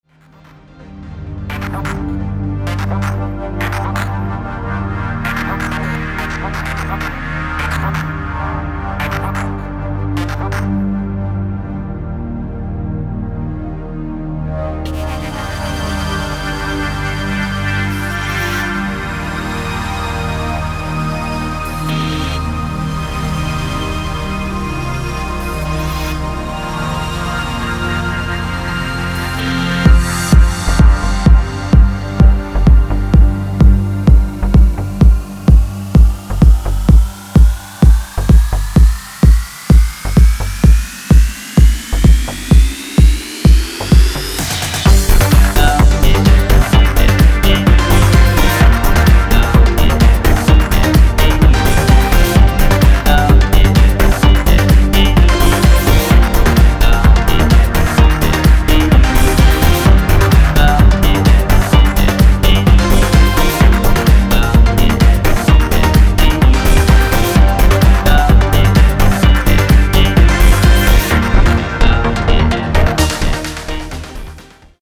Melodic House & Techno